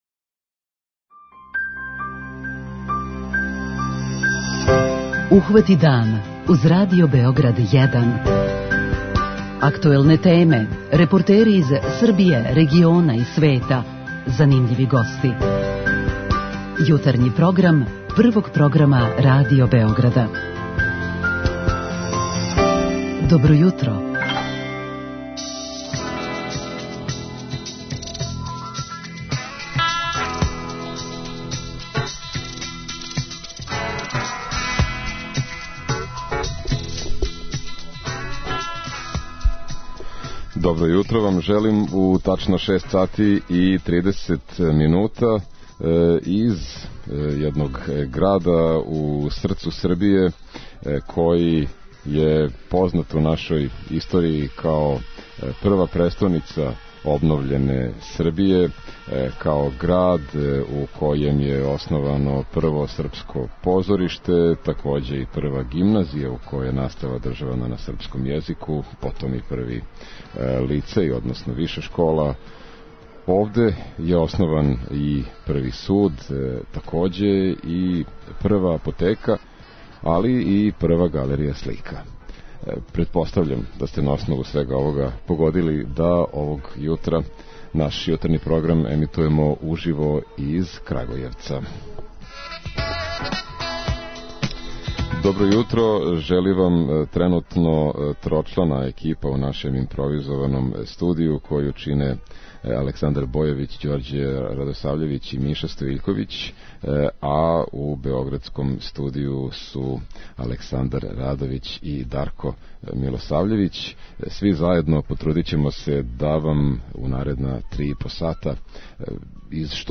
У сред врелог таласа у целој Србији, Радио Београд буди своје слушаоце из Крагујевца, четвртог по величини града у нашој земљи.
С нашим бројним гостима разговараћемо о важним историјским догађајима и личностима везаним за Крагујевац, али и о плановима за развој привреде и будућност града. Представићемо вам и бројне културне и туристичке атракције града, као и поједине спортисте који су своје успехе остварили у овом граду. Чућете и зашто се Крагујевац сматра престоницом лова у Србији.